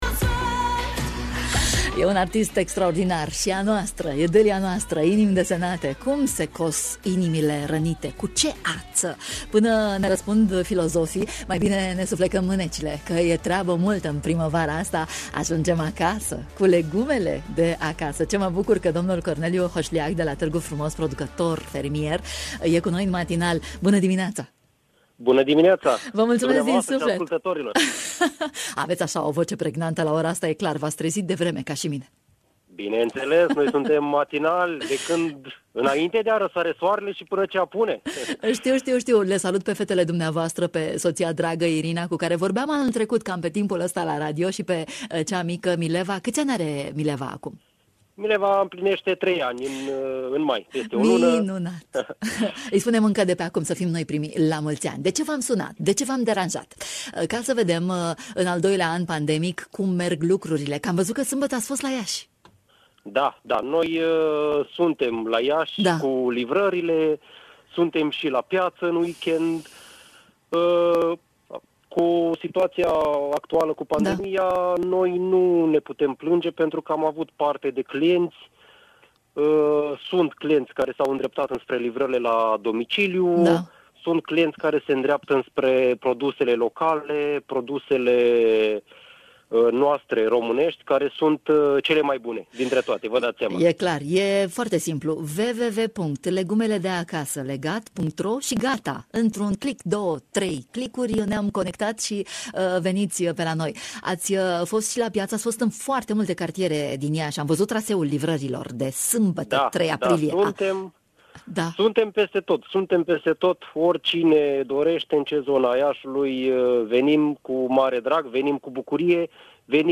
Producătorii de legume din Târgu Frumos, judeţul Iaşi, la microfonul Radio România Iaşi. Cum merge afacerea pe timp de pandemie?!?